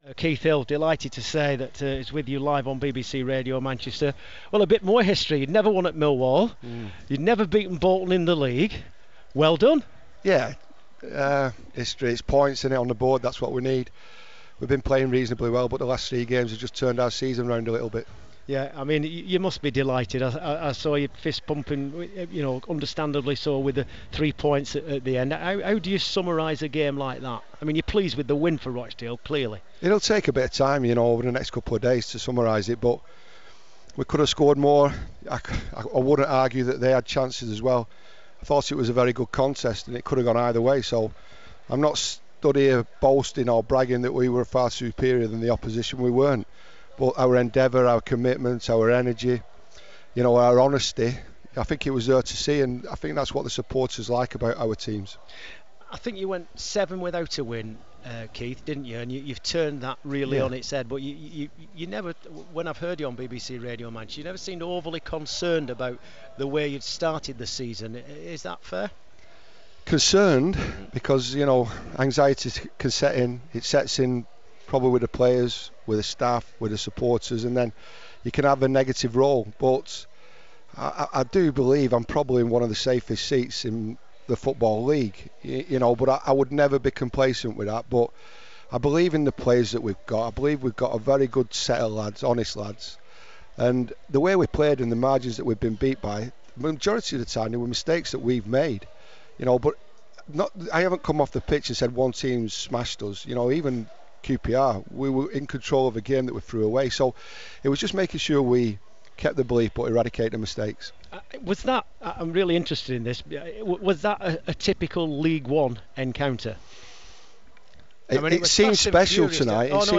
Keith Hill talks to BBC Radio Manchester following his Rochdale side's historic win over local rivals Bolton.